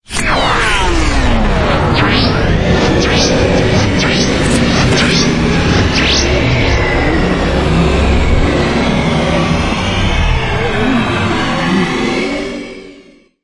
科幻小说的效果 8
Tag: 未来 托管架 无人驾驶飞机 金属制品 金属 过渡 变形 可怕 破坏 背景 游戏 黑暗 电影 上升 恐怖 开口 命中 噪声 转化 科幻 变压器 冲击 移动时 毛刺 woosh 抽象 气氛